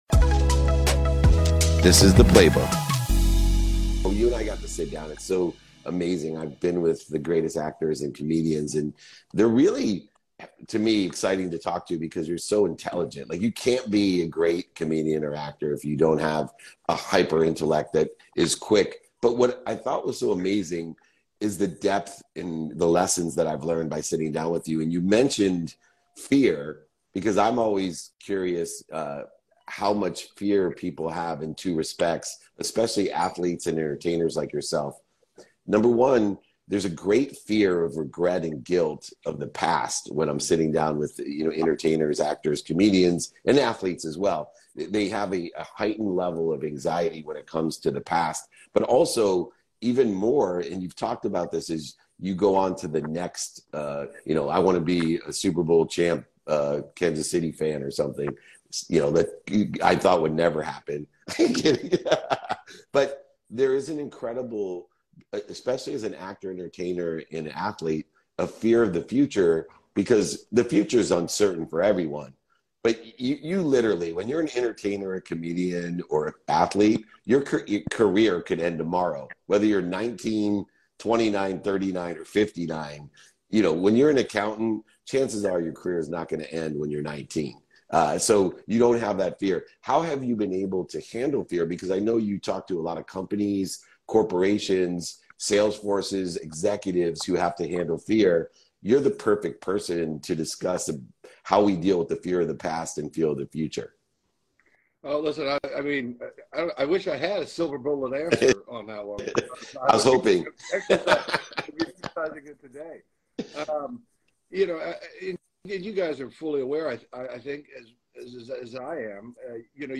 A candid conversation with Rob Riggle on pivoting careers, dealing with fear in life, handling disappointment, how to help young people attain their dreams, and manifesting success.